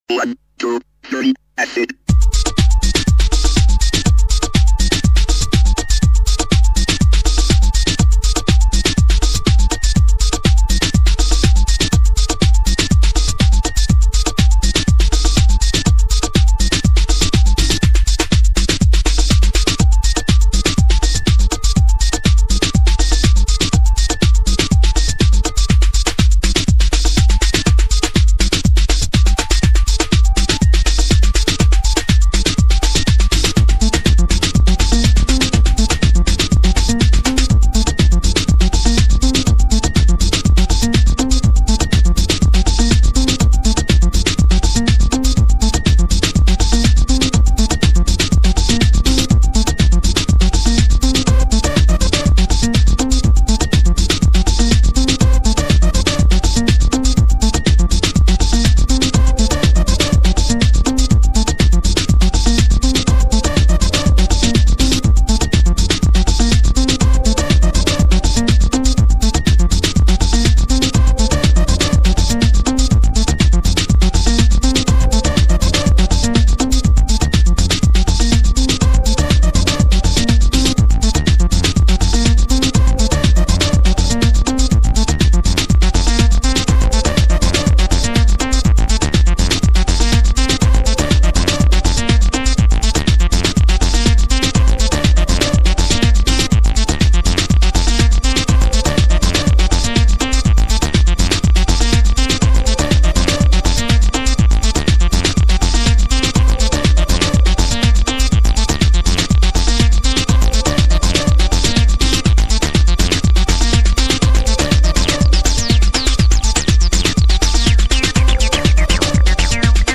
groovin' acid club anthem
House